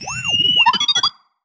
sci-fi_driod_robot_emote_05.wav